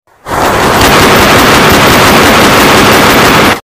MLG Guns Shooting Sound Effects Free Download
MLG - Guns Shooting